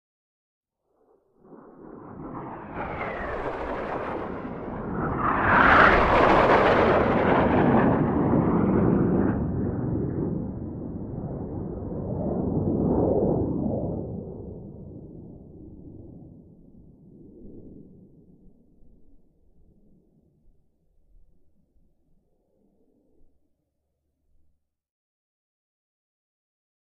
F-5: By Series Of 2: High; Distant And Very Close F-5 Flybys. Medium To Distant Perspective. Jet.